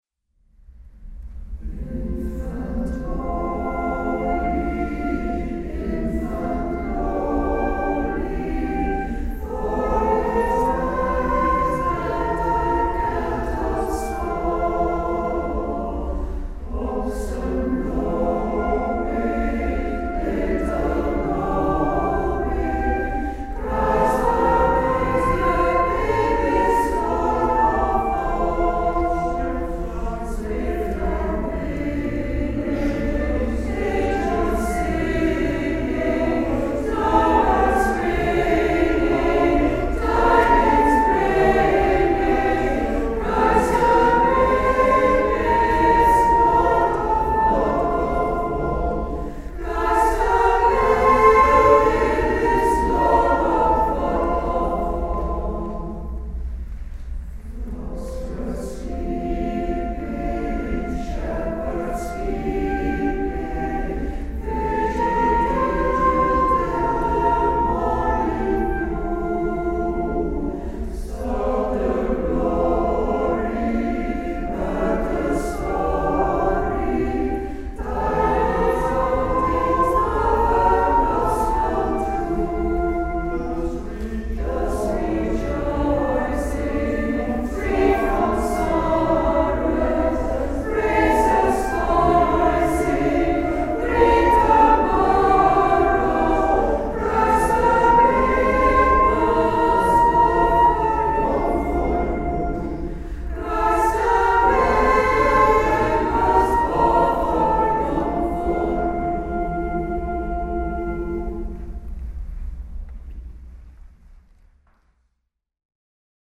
Kerstconcert Dreaming of a white Christmas
Kerk St.-Franciscus Heverlee
Piano en orgel
Dwarsfluit
Infant Holy, infant lowly - Pools kerstlied, bew.